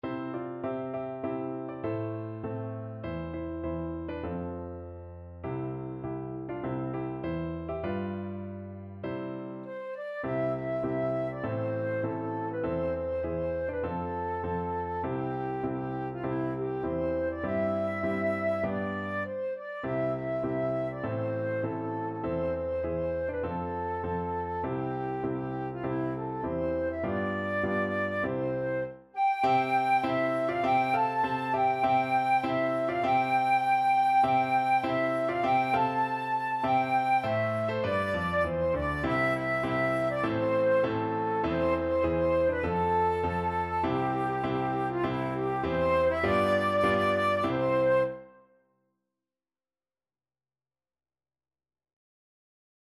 Traditional George Frederick Root Battle Cry of Freedom (Rally Round The Flag) Flute version
4/4 (View more 4/4 Music)
C major (Sounding Pitch) (View more C major Music for Flute )
Moderato
Traditional (View more Traditional Flute Music)